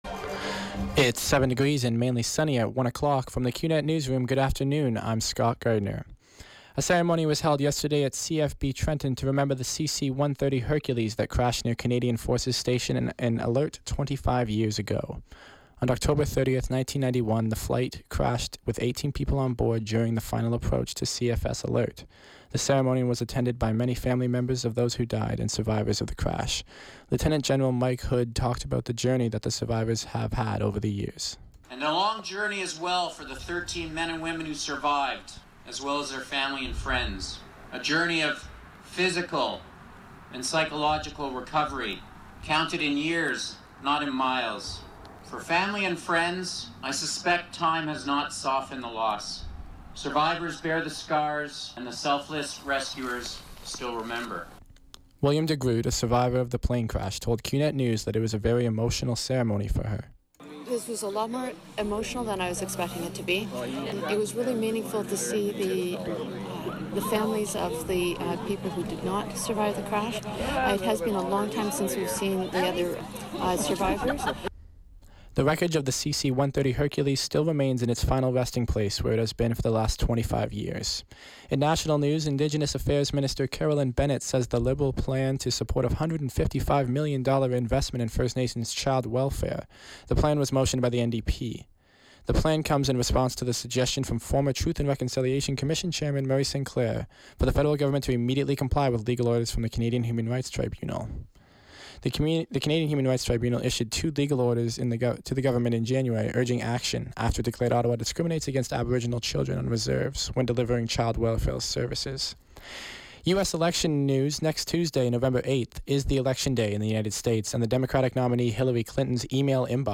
91X FM Newscast – Monday, Oct. 31, 2016, 1 p.m.